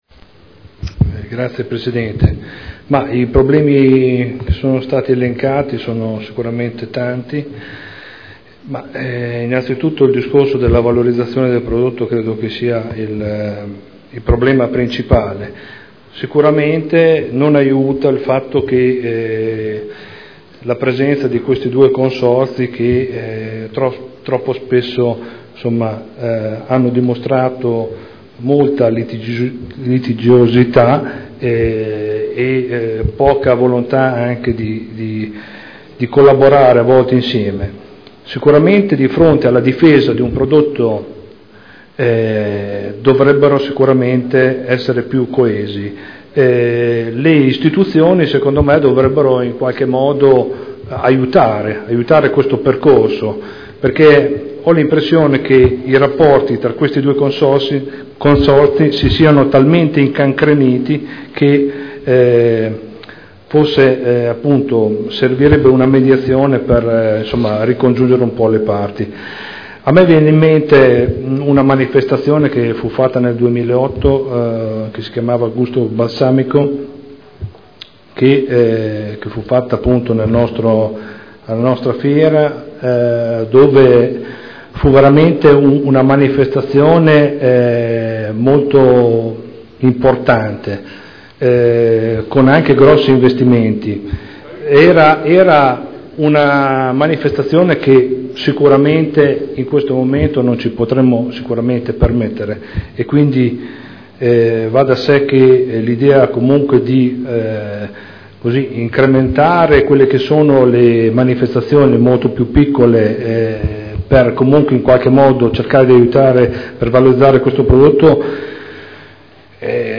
Stefano Prampolini — Sito Audio Consiglio Comunale